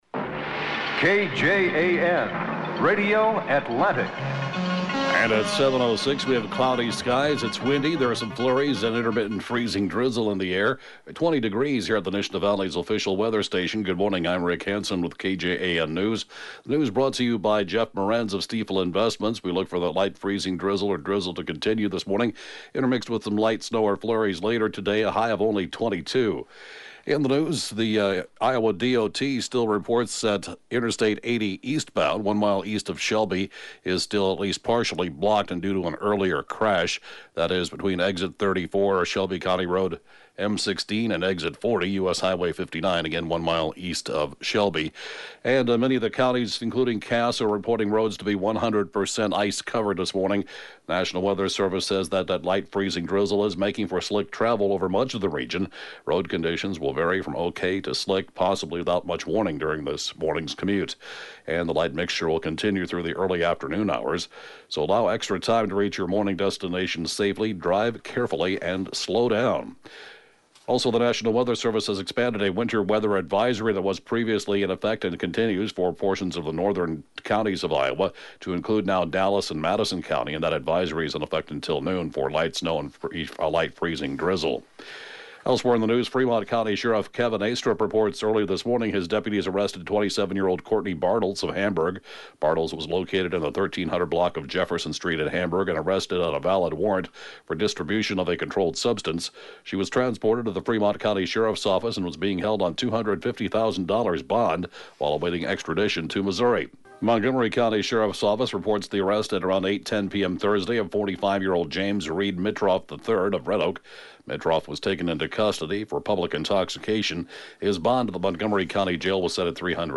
7AM Newscast 02/02/2019